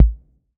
6 BD 2.wav